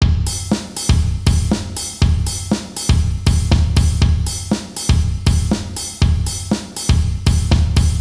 rythme de batterie à contre temps avec ouvertures charley
Le charley sera joué seulement sur les ET de chaque temps avec des ouvertures au pied.
Comme vous avez pu l’entendre les contre temps sont marqués par des ouvertures charley.
contre-temps-rythme-final-120-bpm.wav